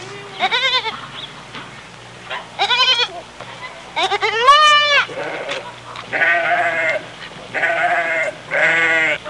Baby Lamb Sound Effect
Download a high-quality baby lamb sound effect.
baby-lamb-1.mp3